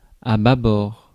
France (Paris)